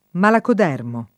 [ malakod $ rmo ]